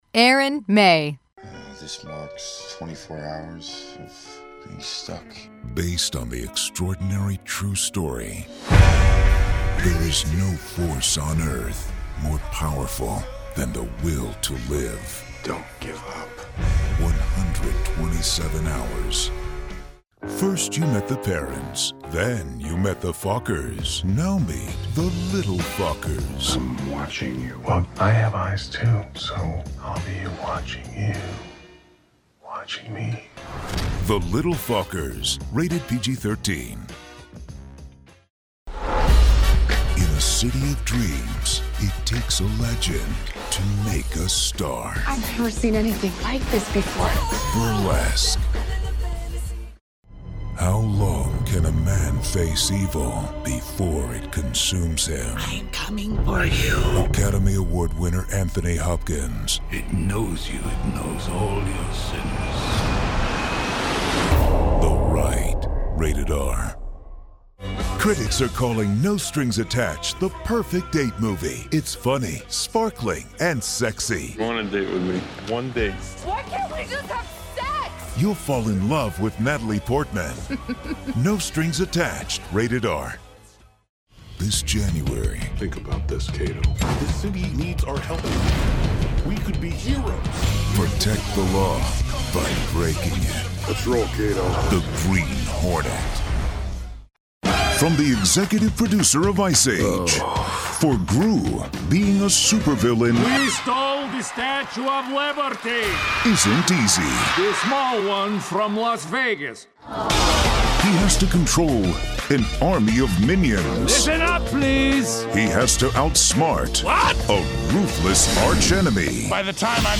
Male VOs